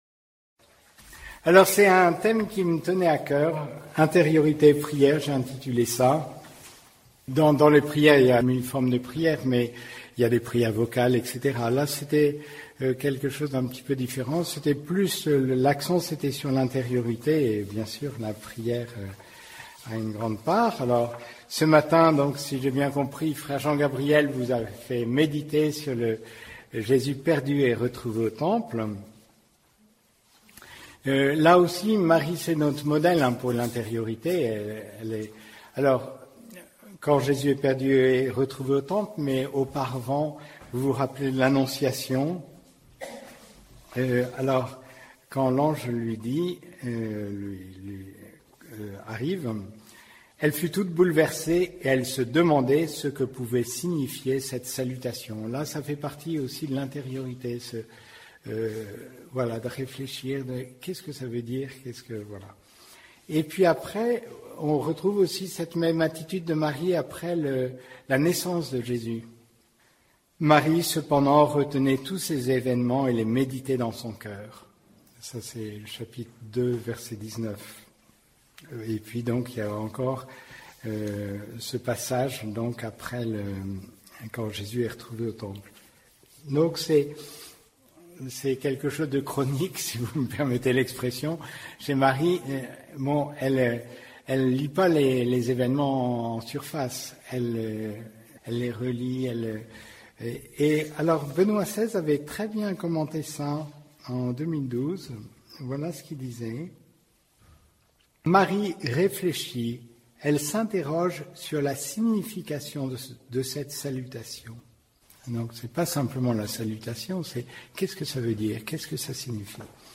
Enseignement Halte Spirituelle d’Ourscamp - 2 - L’intériorité (Novembre 2024)